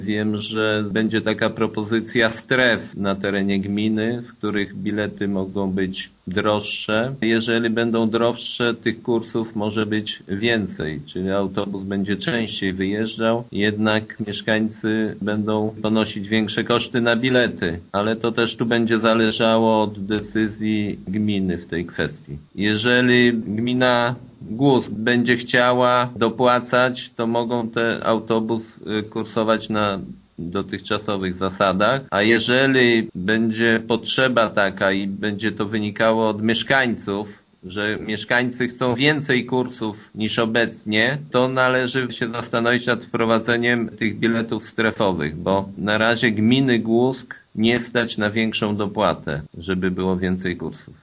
Według wójta Anasiewicza jest szansa, że w przyszłości autobusy w gminie Głusk będą kursować częściej. Samorząd czeka teraz na propozycje, które przedstawi w tej sprawie ZTM w Lublinie: